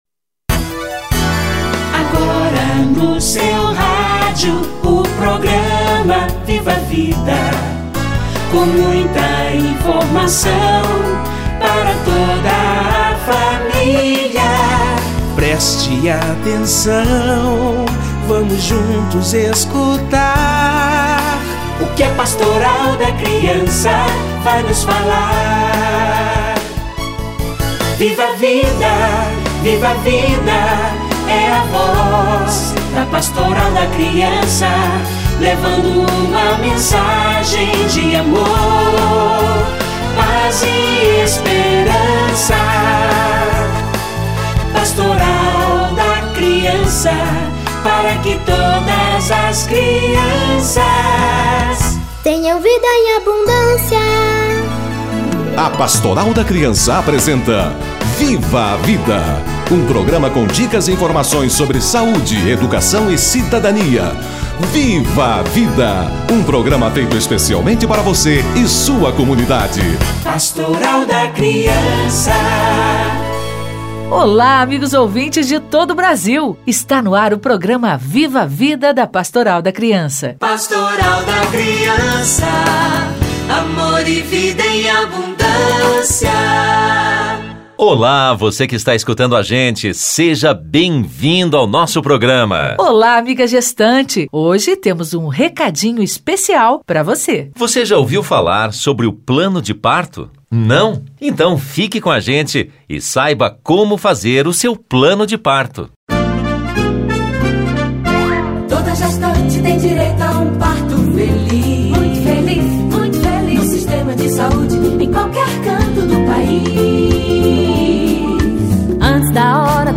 Plano de parto - Entrevista